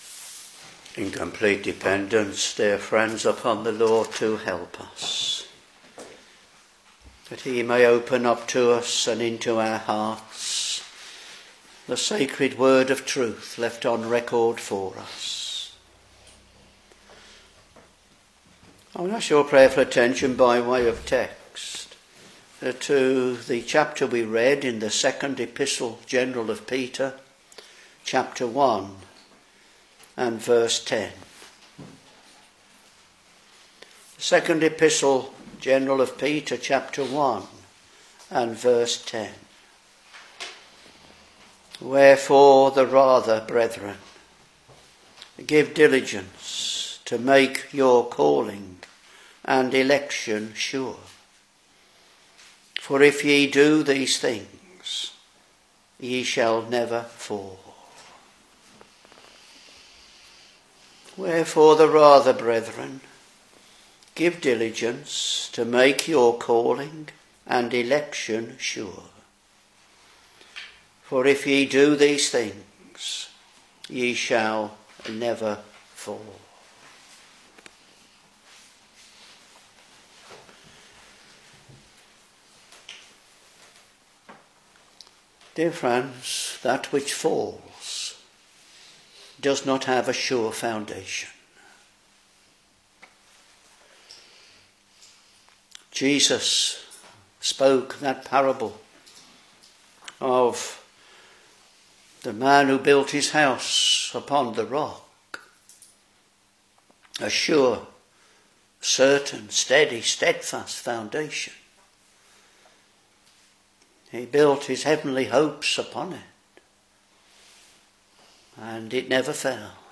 Back to Sermons 2 Peter Ch.1 v.10 Wherefore the rather, brethren, give diligence to make your calling and election sure: for if ye do these things, ye shall never fall